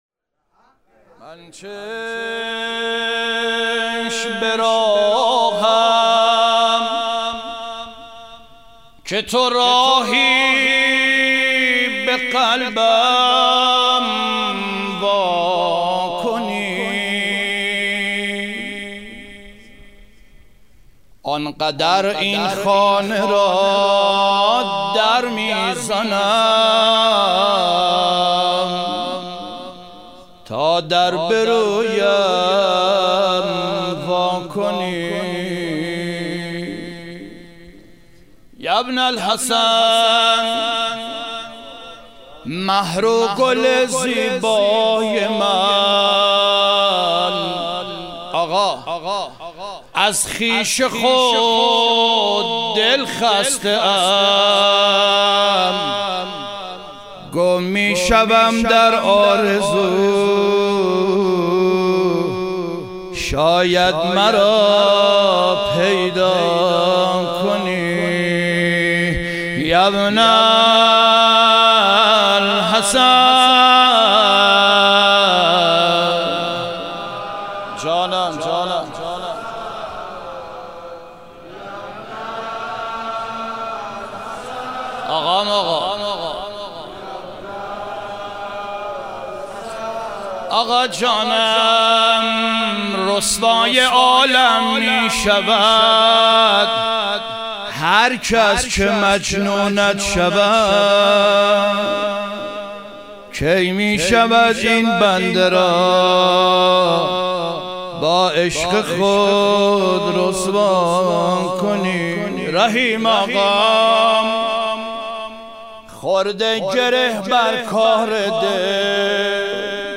مدح و رجز
شب ظهور وجود مقدس امام حسن عسکری علیه السلام